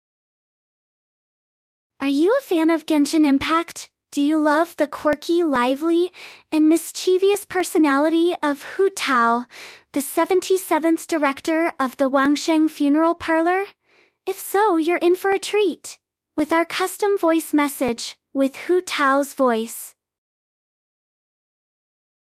Custom Voice Message with Hu Tao Voice
You’ll recognize her distinctive voice, playful tone, and the charm that makes her one of the most beloved characters in Genshin Impact.
Our voice actors and advanced AI technology ensure that the message you receive is a high-quality recording that accurately mimics Hu Tao’s voice.
Her voice is playful, mischievous, and full of energy, making her the perfect character for a custom voice message.
Custom-Voice-Message-with-Hu-Tao-Voice.mp3